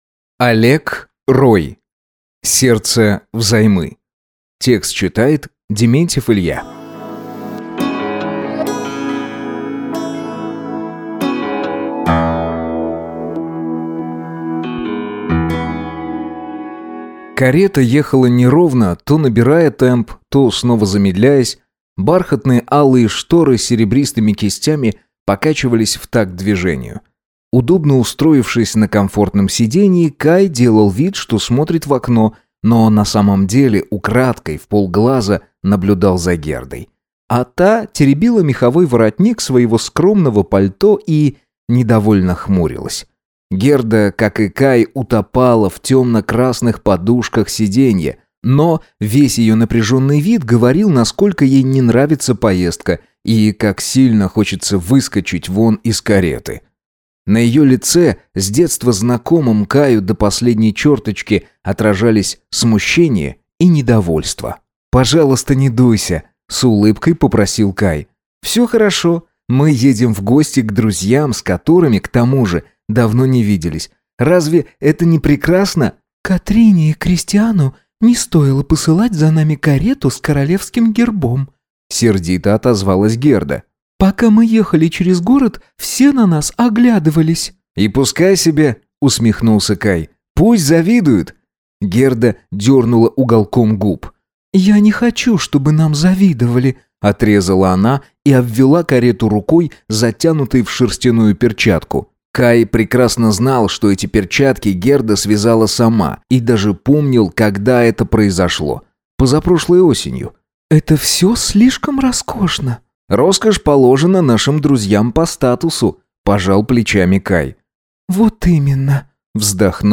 Аудиокнига Сердце взаймы | Библиотека аудиокниг
Прослушать и бесплатно скачать фрагмент аудиокниги